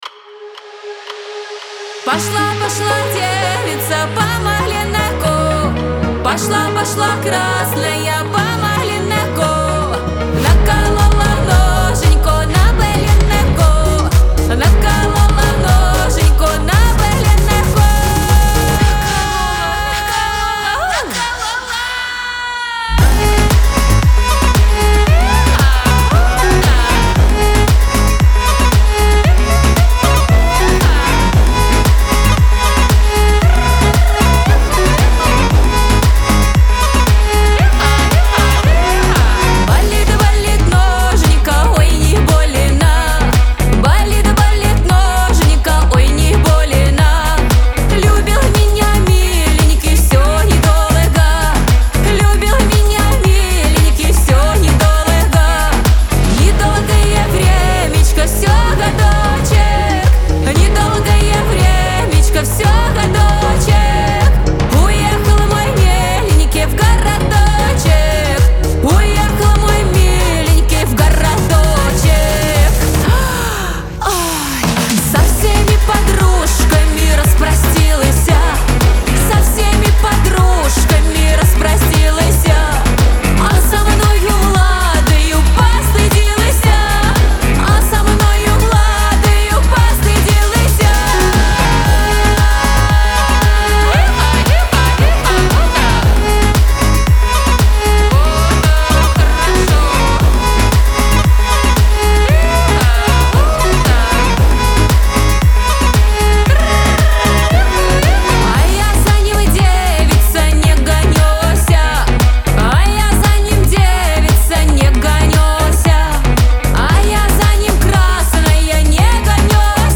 Веселая музыка
эстрада
диско